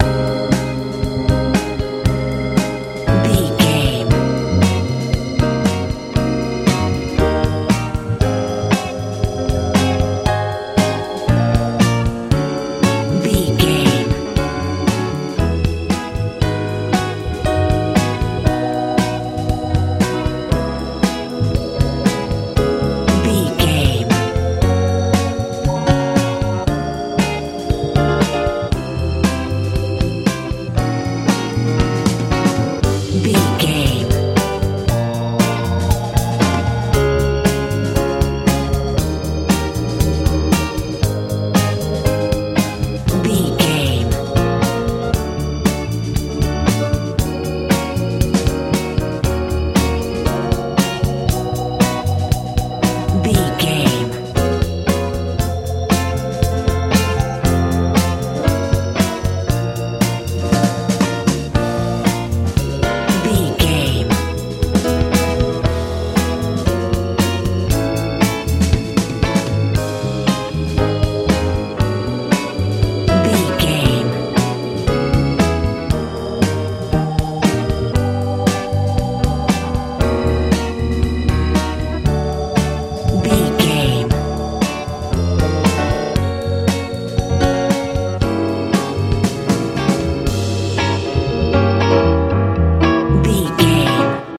60s sou
Ionian/Major
groovy
funky
organ
electric guitar
piano
bass guitar
drums